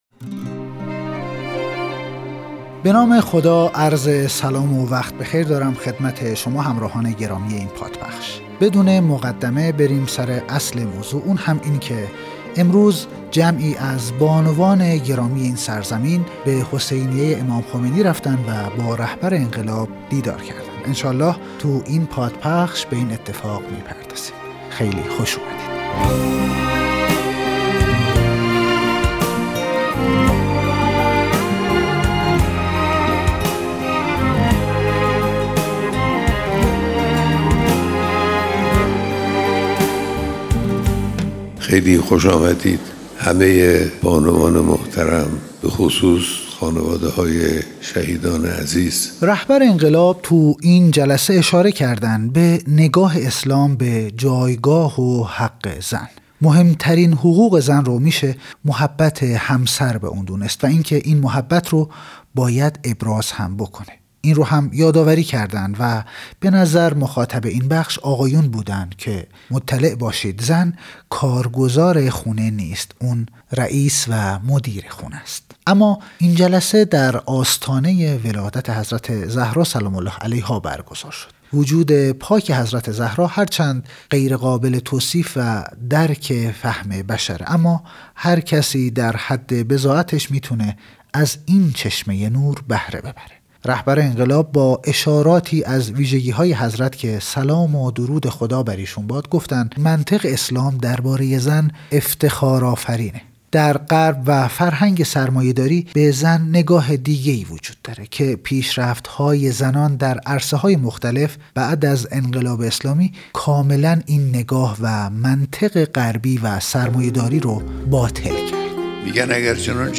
بیانات در دیدار هزاران نفر از زنان و دختران
مرور صوتی بیانات رهبر انقلاب در دیدار هزاران نفر از زنان و دختران